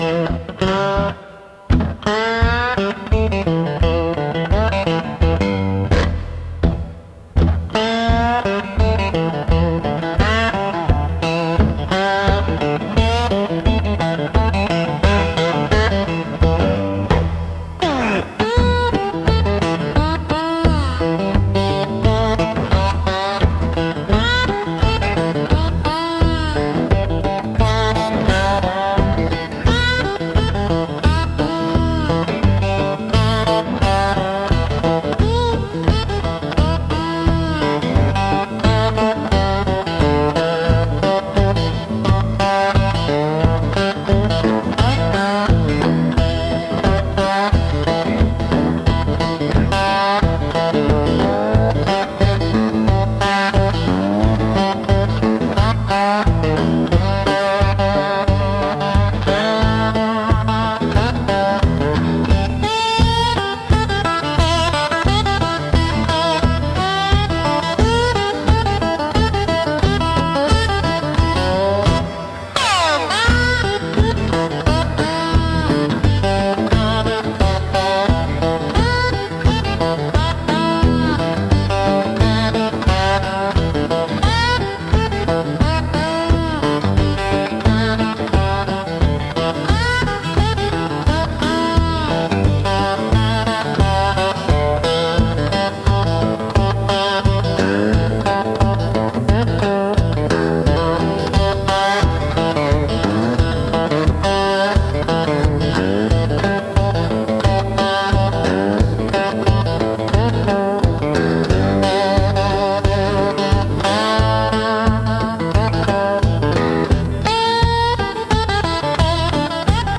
Instrumental -